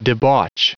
Prononciation du mot debauch en anglais (fichier audio)
Prononciation du mot : debauch